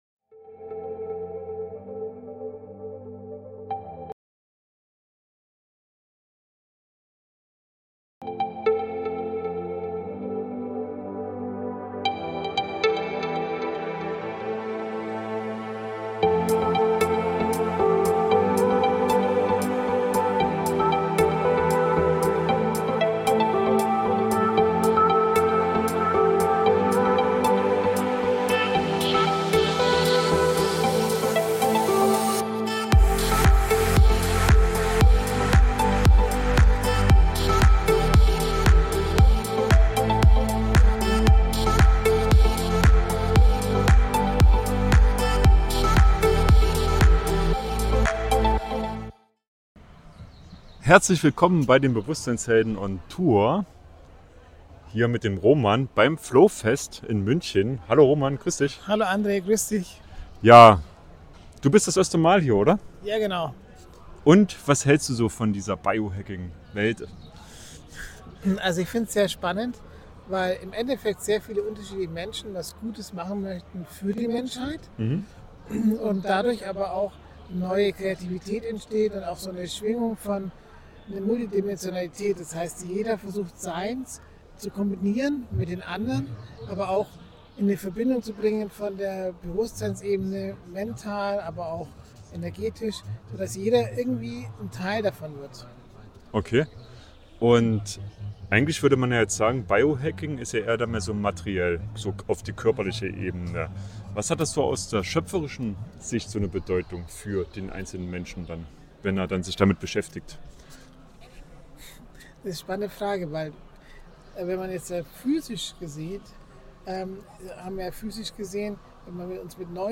Biohacking aus der Schöpfersicht | Auf dem Flowfest 2025